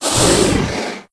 c_hakkar_hit2.wav